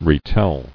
[re·tell]